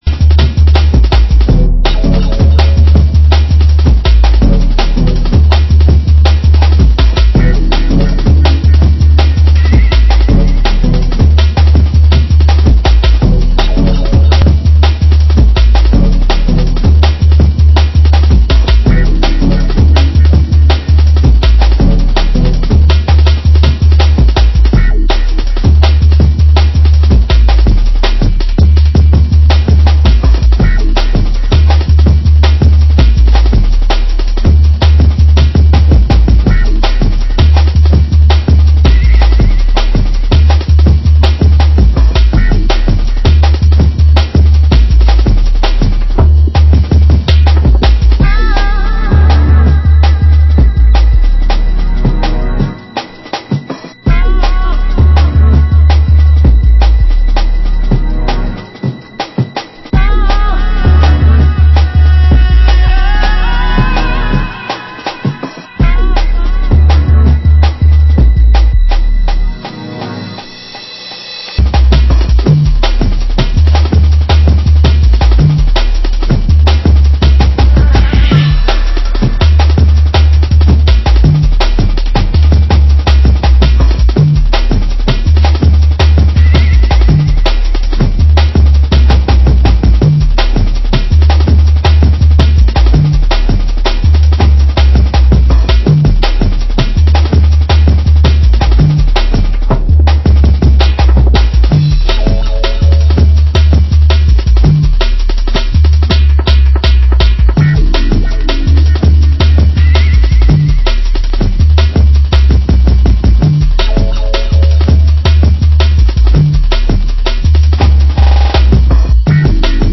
Genre: Jungle